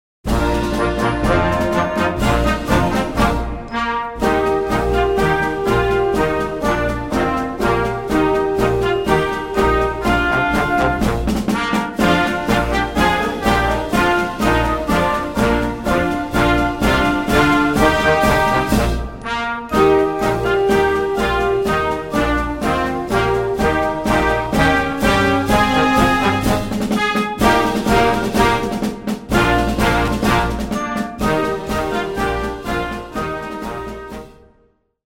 marsze